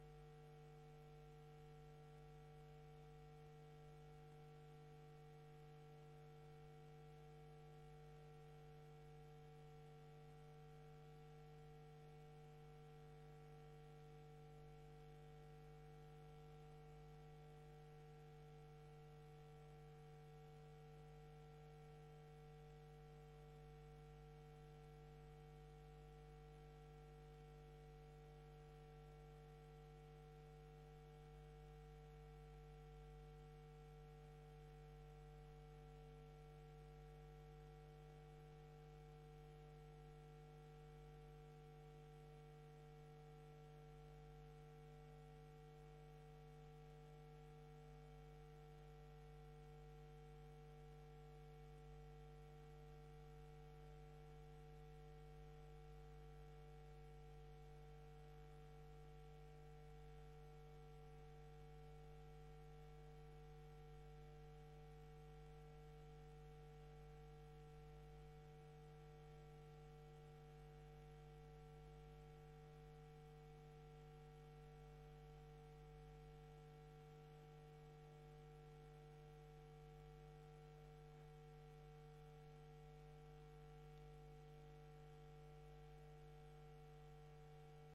Gemeenteraad 06 november 2024 19:30:00, Gemeente Hof van Twente